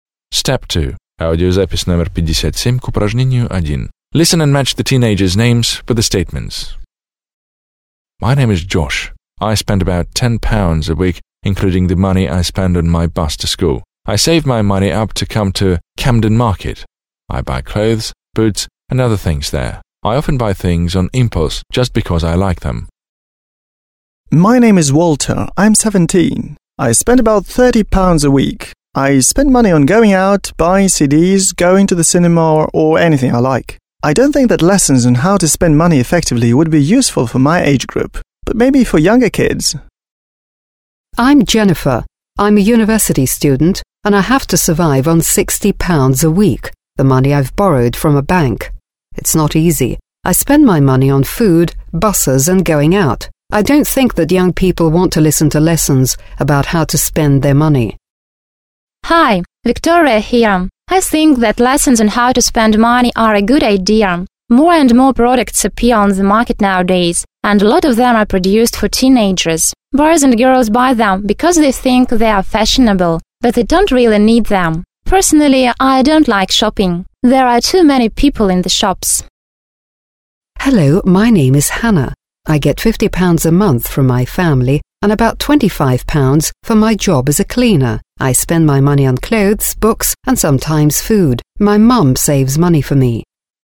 1. Listen to what five British teenagers (1-5) think about the way they spend money, (57), and match their names with the statements (a-f).